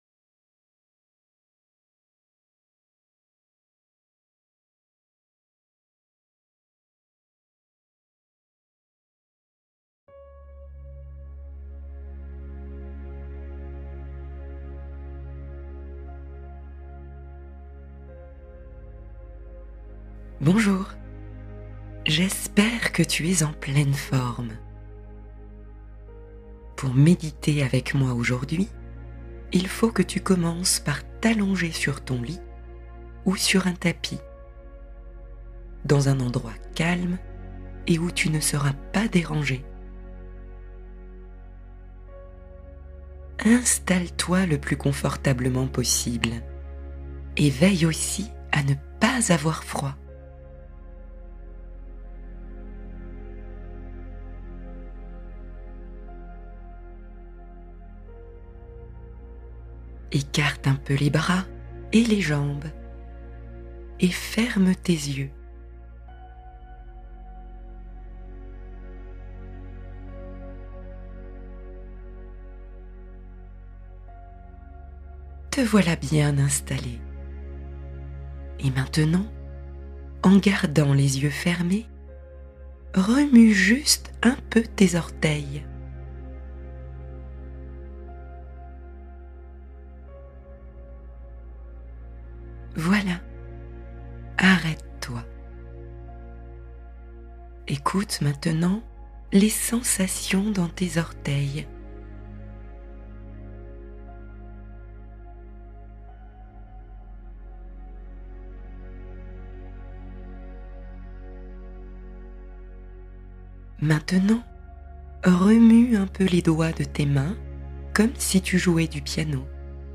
Le trésor : histoire apaisante pour se recentrer en douceur